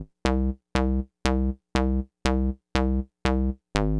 TSNRG2 Off Bass 005.wav